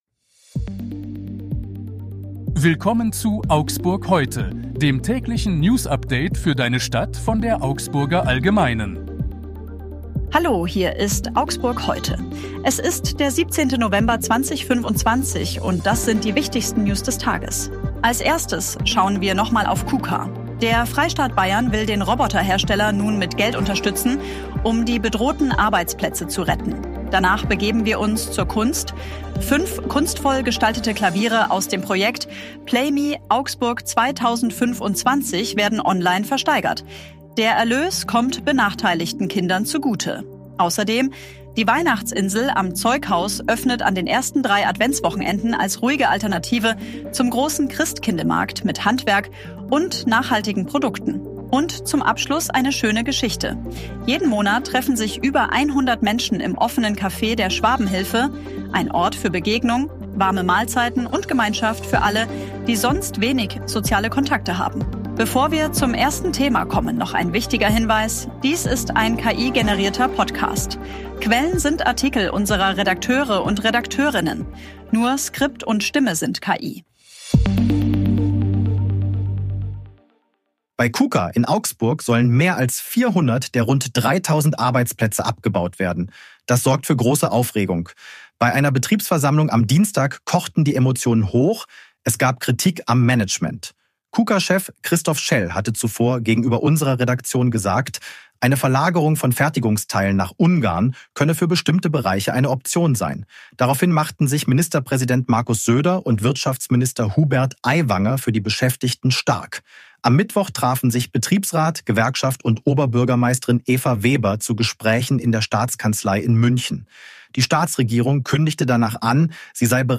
Hier ist das tägliche Newsupdate für deine Stadt.
und einsame Menschen Dies ist ein KI-generierter Podcast.
Stimme sind KI.